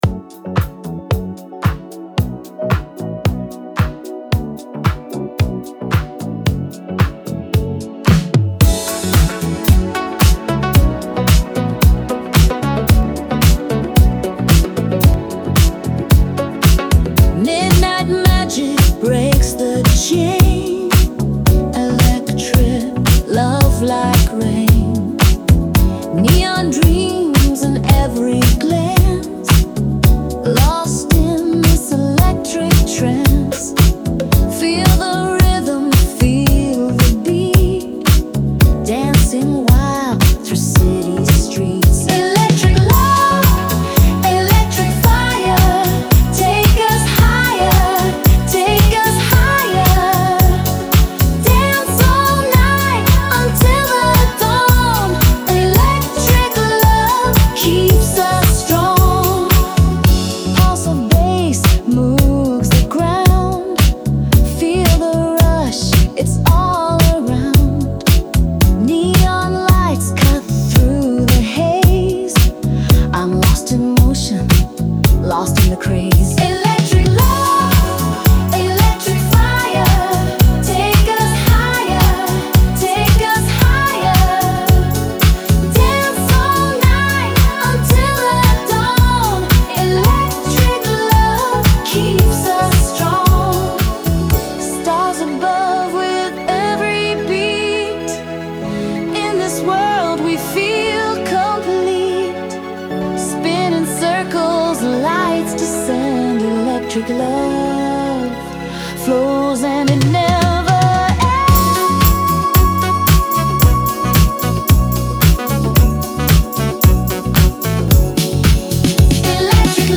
Dive into our latest indie pop-rock releases.
dance-tinged